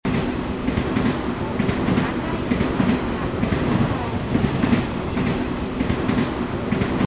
騒音下で聞き取りやすいように音声を加工処理した例　［修士学生の研究よ り］
（少し音量を上げて注意して聞いて下さい．騒音や音声の音量は，どちらも 各々同じです．）
未加工音声